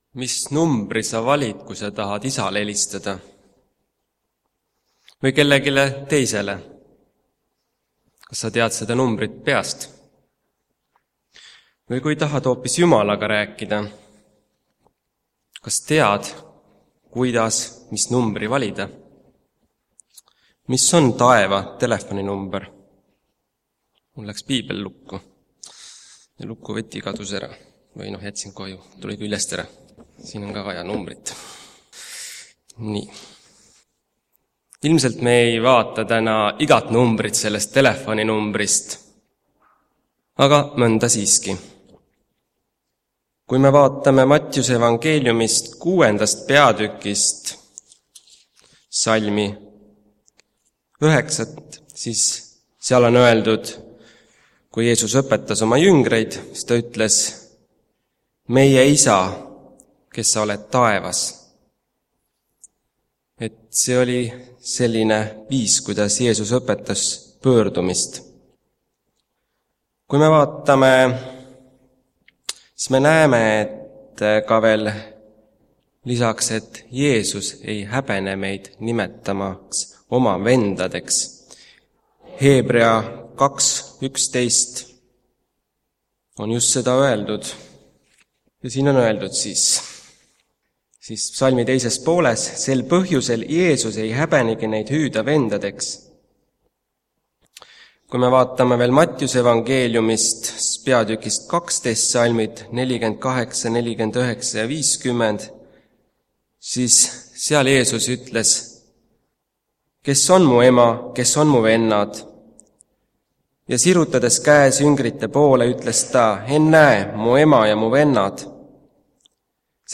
Jutlused